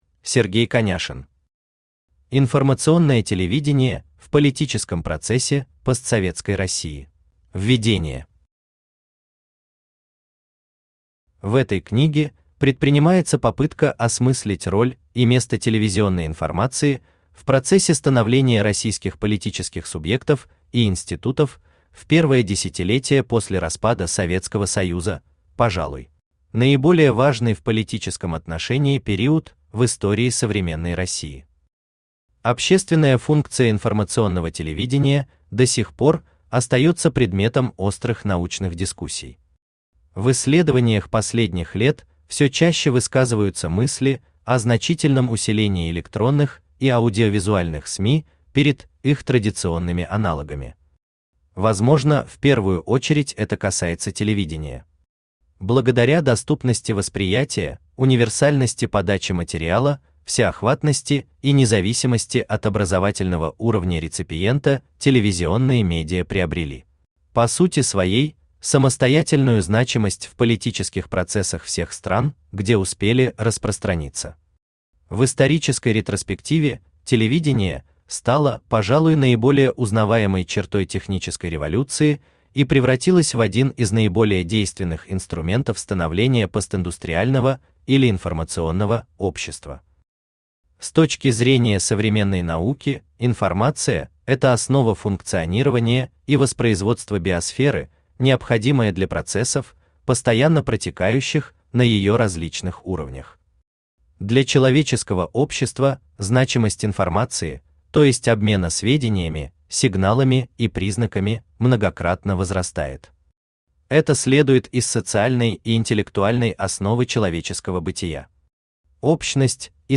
Аудиокнига Информационное телевидение в политическом процессе постсоветской России | Библиотека аудиокниг
Aудиокнига Информационное телевидение в политическом процессе постсоветской России Автор Сергей Сергеевич Коняшин Читает аудиокнигу Авточтец ЛитРес.